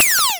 laser4.wav